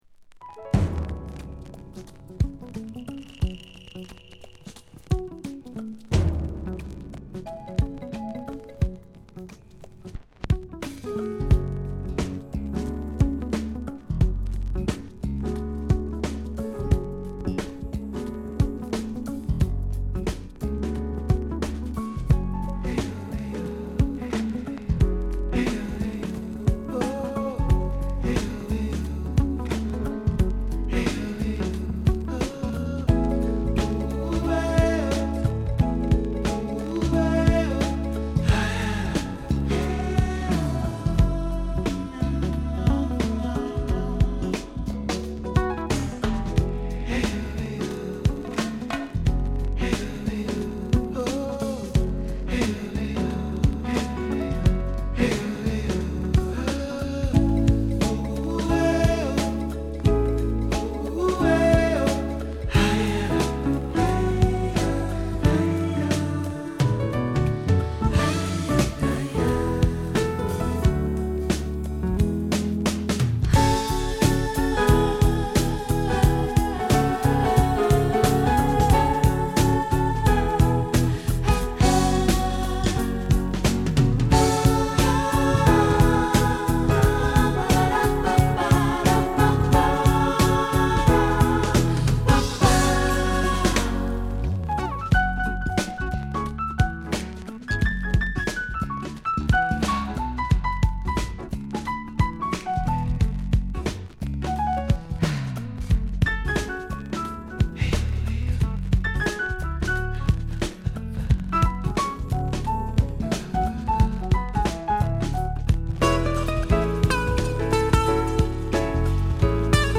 サウンドのクオリティ、演者のスキル共々、完成度の高い一曲です。